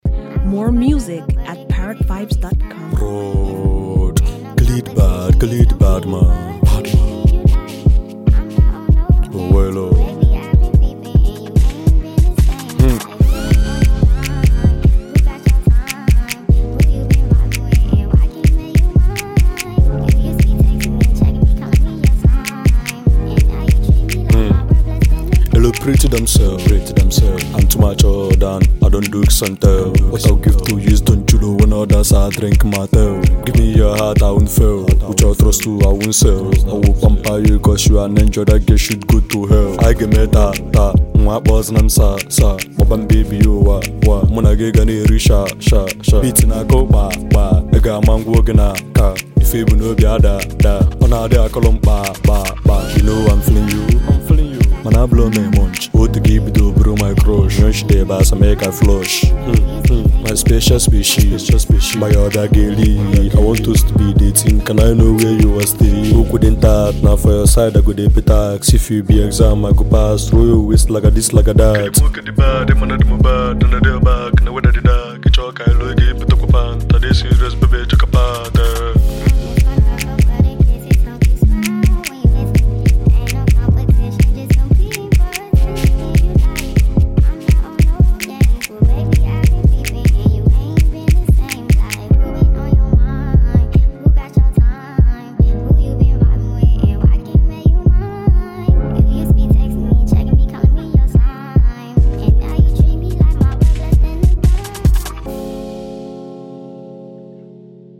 sweet-sounding single